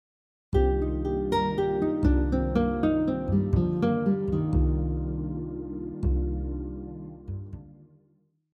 Example 4 includes arpeggio note skipping, scale notes and a hint of the minor pentatonic scale in measure 2.
minor 7 arpeggio example 4